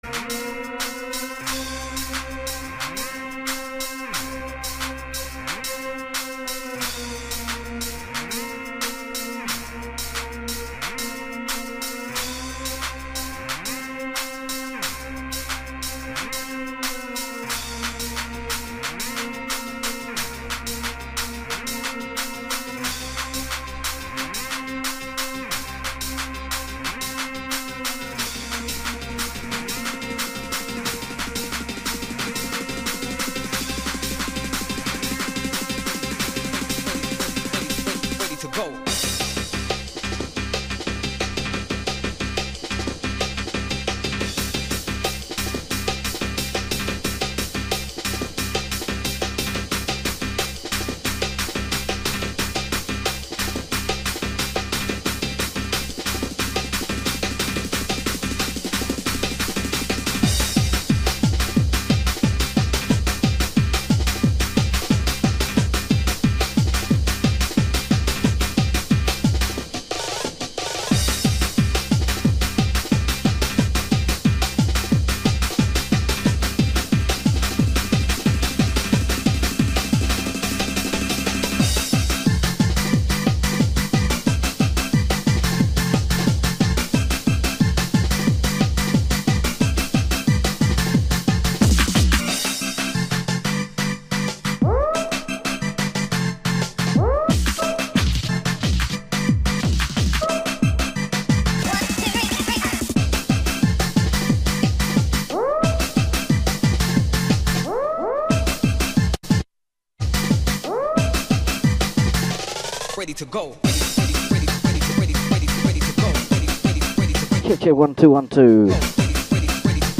Saturday Mixage
Live Recording
Happy Hardcore Oldskool Hardcore